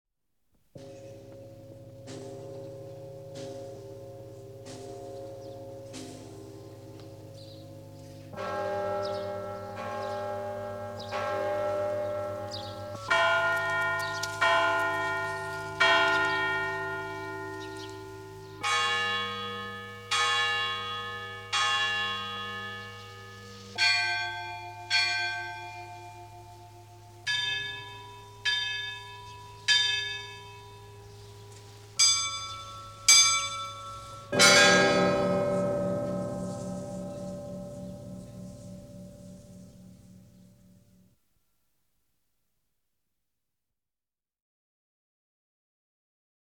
Index of /lib/fonoteka/etnic/rossia/kolokola/rostov-2
10_Perezvon.mp3